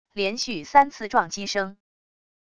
连续三次撞击声wav音频